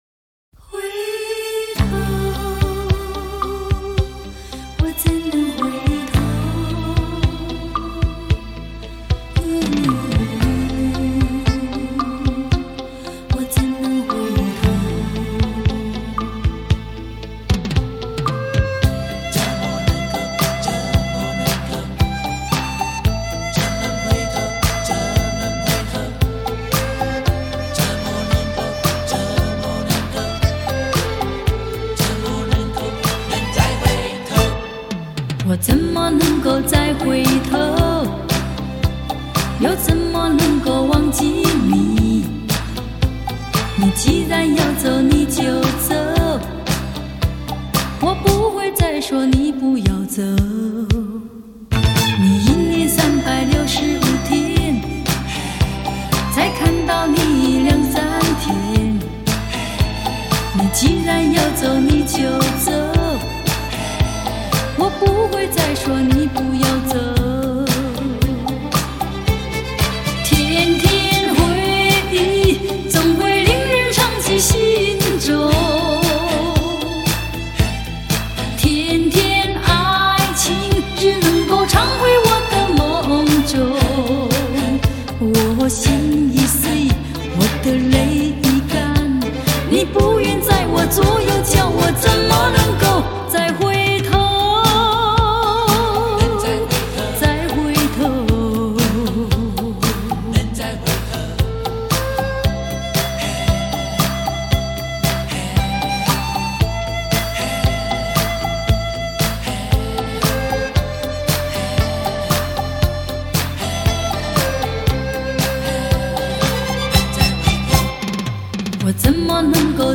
录音室：Oscar Studio S'pore